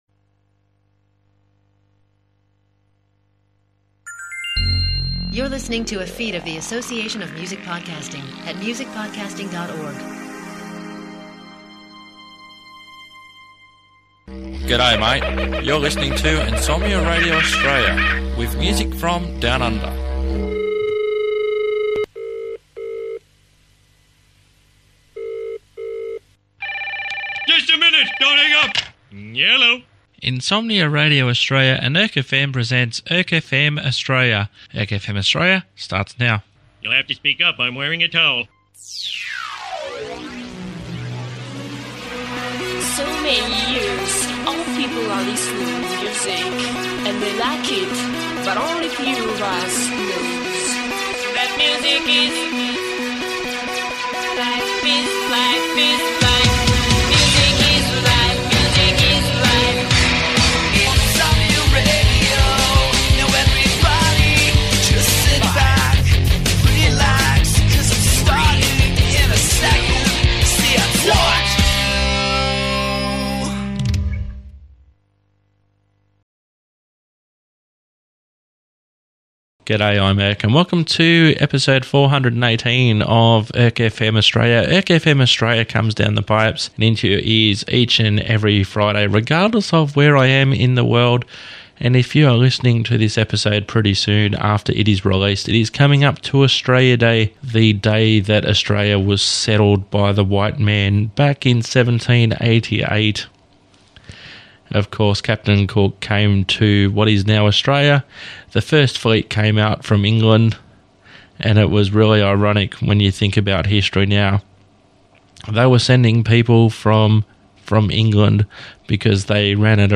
mixed multi-genre sampler